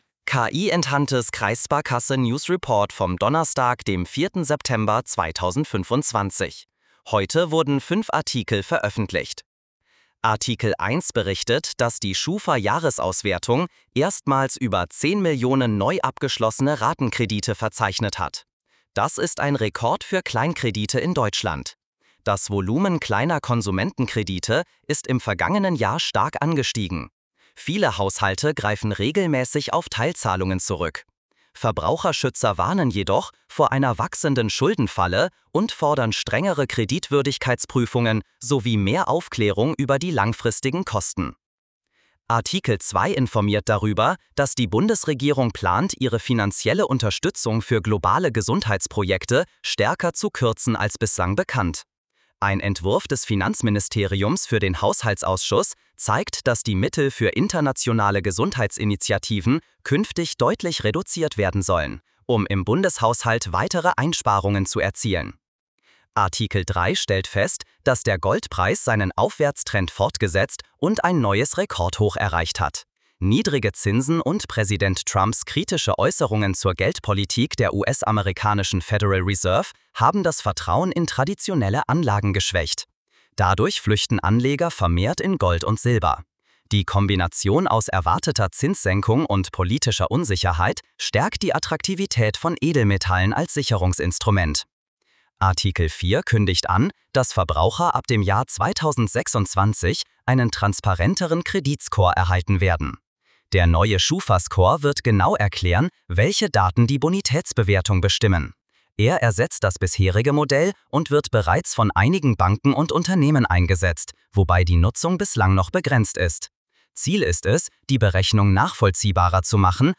🎧 KI-Enhanced News Audio Reports - Smart & Intelligent
🤖 Automatisch generiert mit n8n und KI